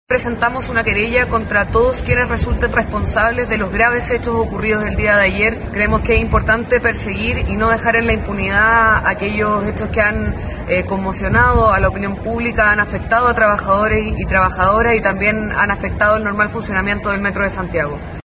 La delegada presidencial para la región Metropolitana, Constanza Martínez, y frente a la gravedad de lo sucedido, anunció la presentación de acciones legales.